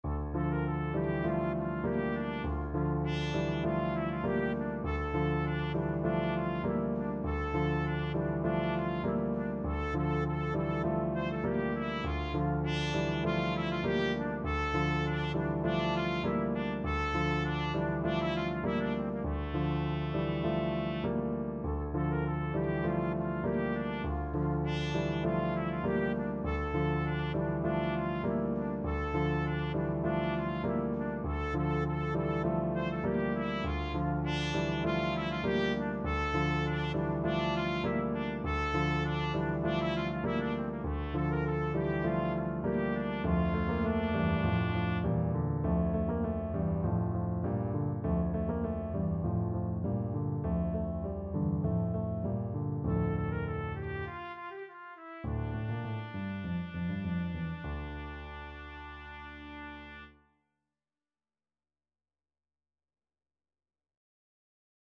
4/4 (View more 4/4 Music)
Allegro (View more music marked Allegro)
Classical (View more Classical Trumpet Music)